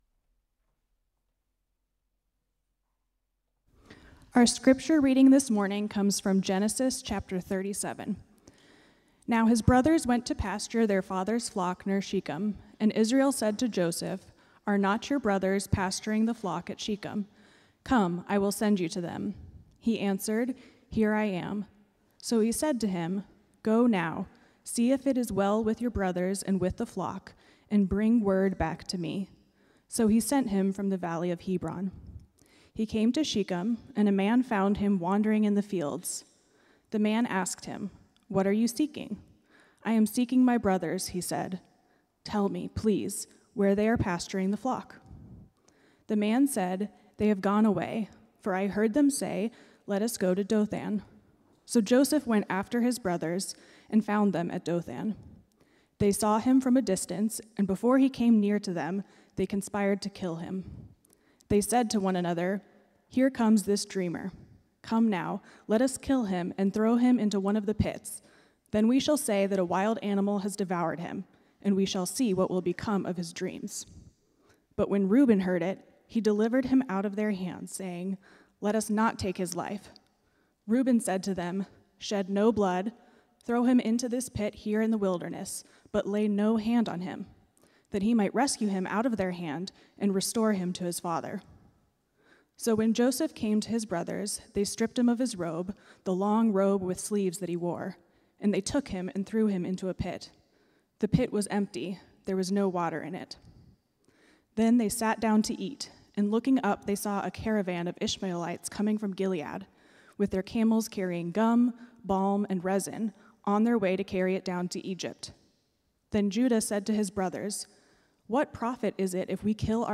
First Cary UMC's First on Chatham Sermon